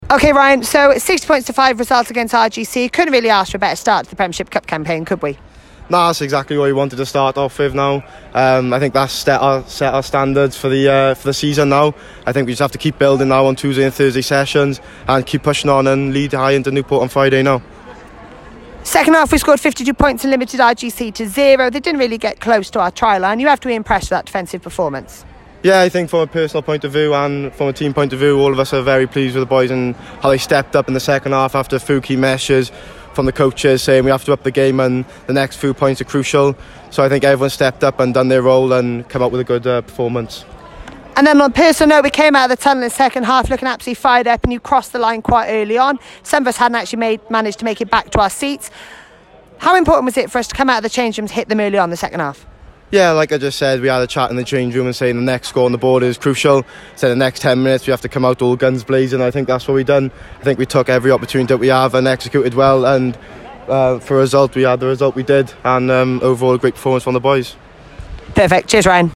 Post Match Interviews